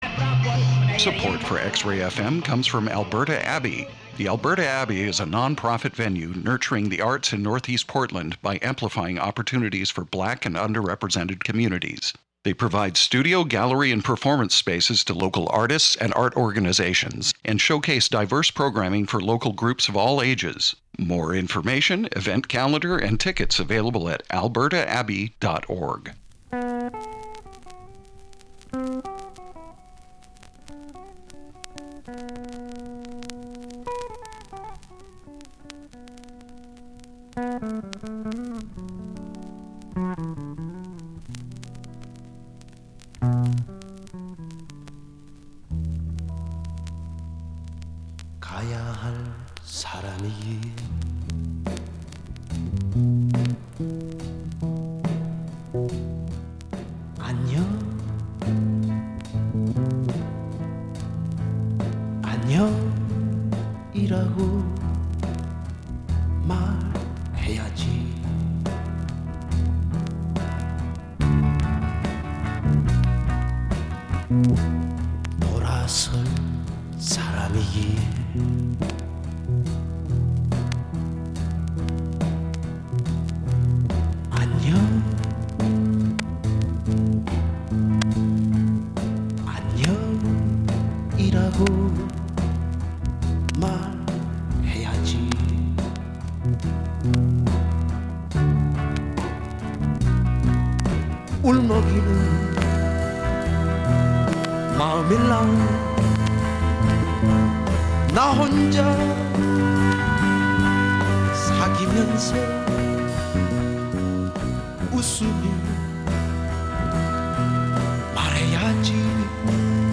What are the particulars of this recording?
All original vinyl, from all over the world.